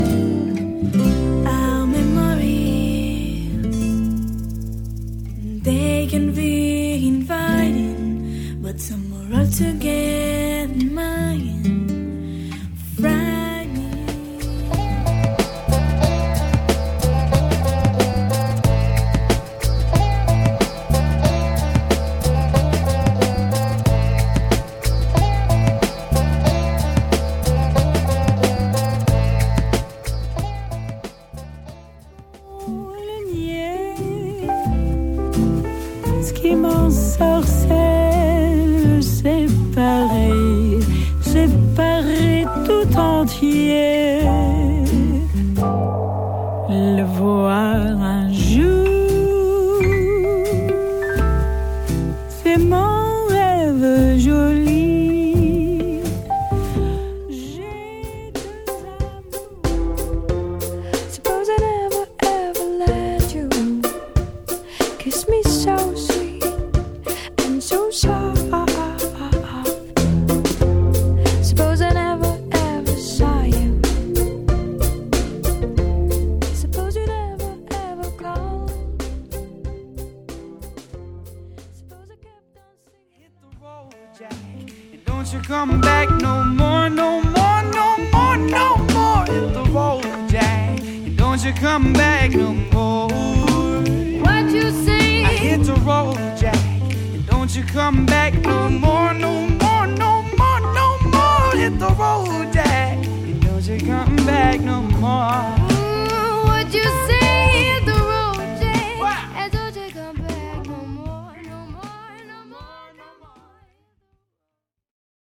Eclectic Mix of Easy Listening Songs